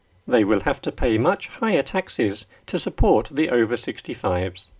DICTATION 9